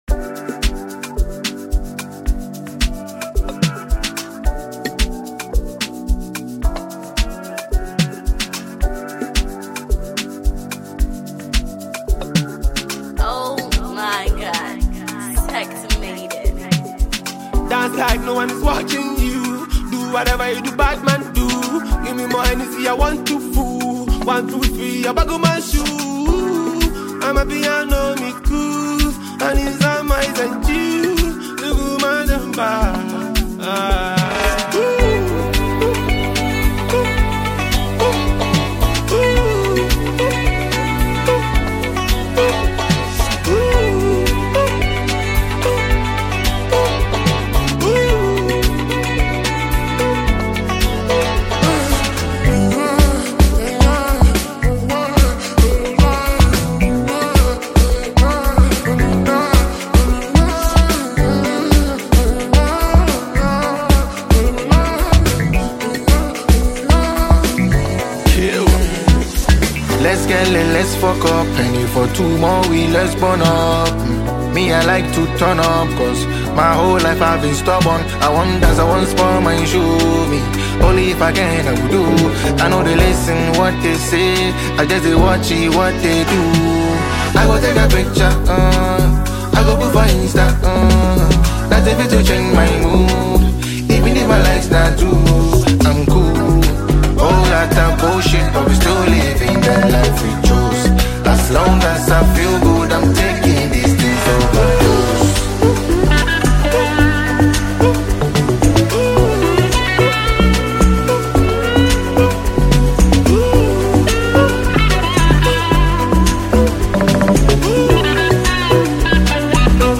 Ghanaian rapper
banging record